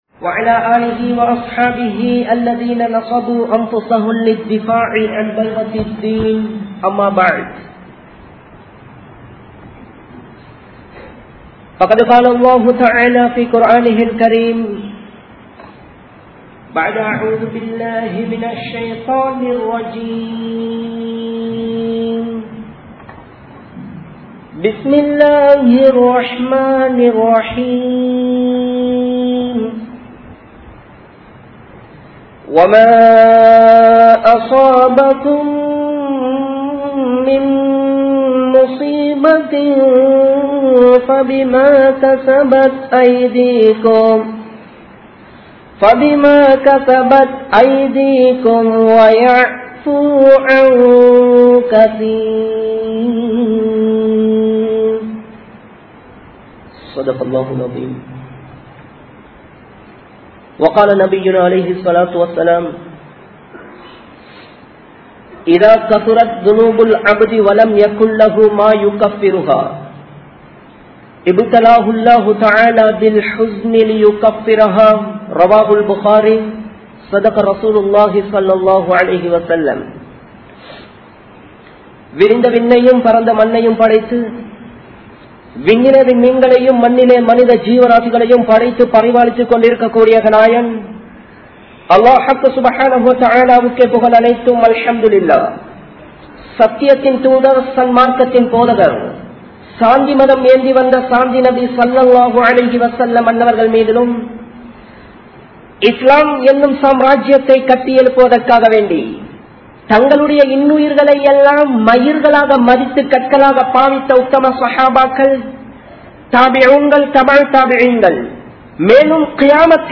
Paavaththin Bayangaramaana Vilaivuhal (பாவத்தின் பயங்கரமான விளைவுகள்) | Audio Bayans | All Ceylon Muslim Youth Community | Addalaichenai